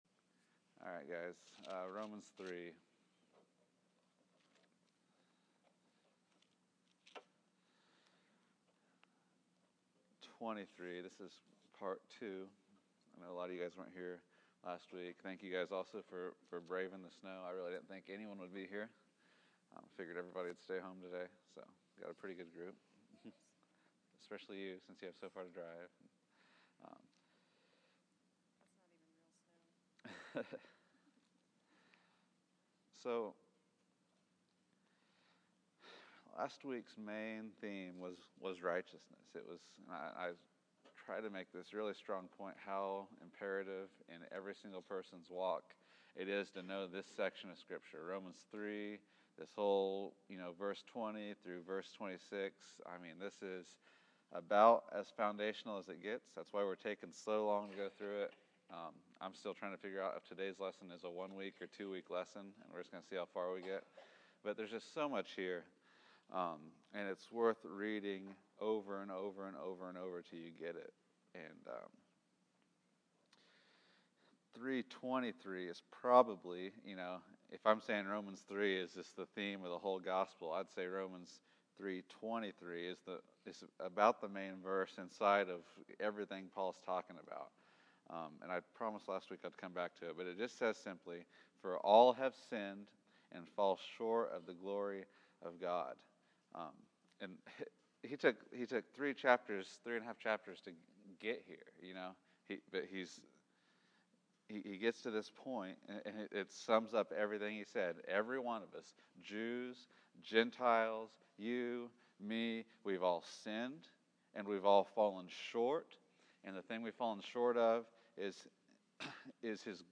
Romans 3:23-25 February 22, 2015 Category: Sunday School | Location: El Dorado Back to the Resource Library Falling short of His glory.